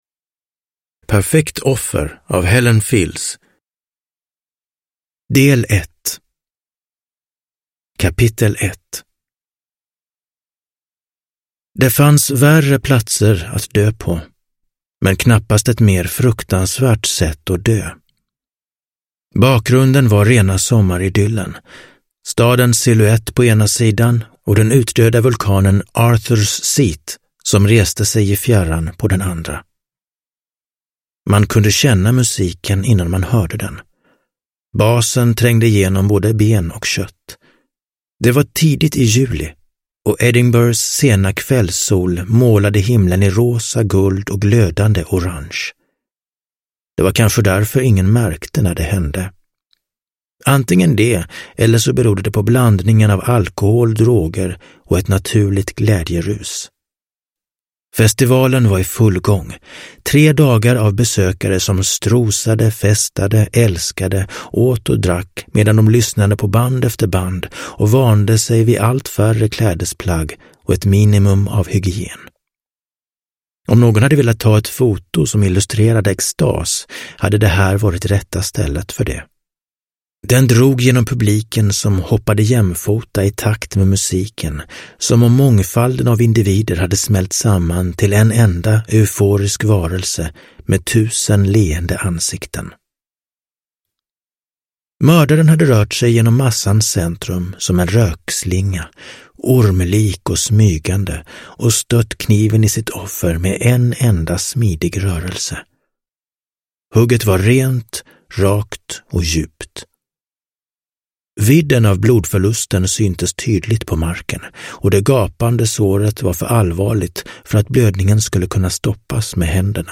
Perfekt offer – Ljudbok – Laddas ner